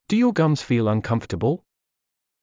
ﾄﾞｩ ﾕｱ ｶﾞﾑｽﾞ ﾌｨｰﾙ ｱﾝｶﾝﾌｧﾀﾎﾞｳ